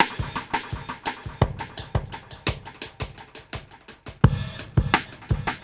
Index of /musicradar/dub-drums-samples/85bpm
Db_DrumsA_KitEcho_85_01.wav